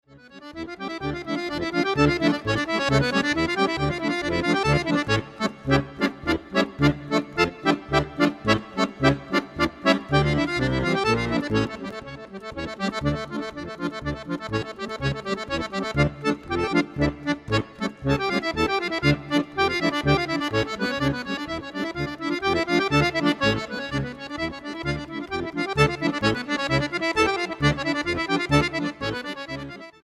acordeón clásico
vihuela y guitarra renacentistas
percusiones
guitarra española y percusiones